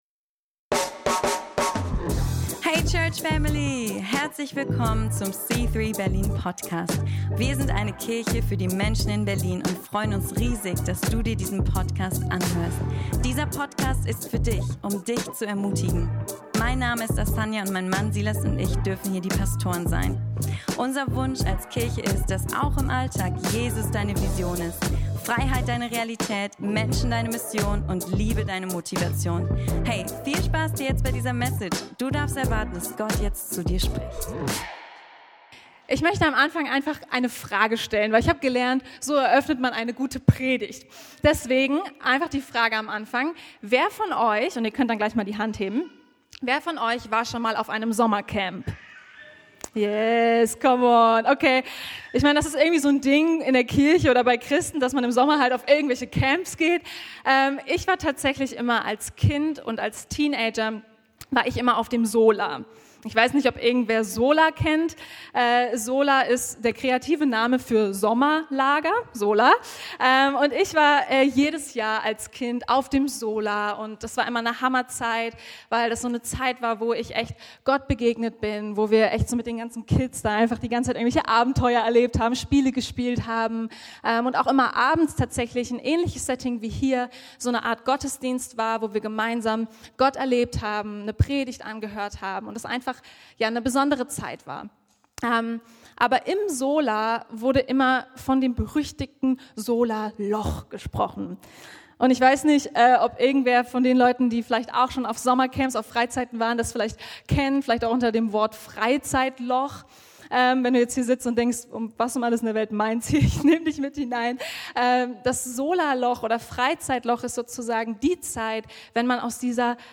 Die heutige Predigt wird dich ermutigen und dir praktische Schritte geben, wie du wirklich langfristige Veränderung erleben wirst.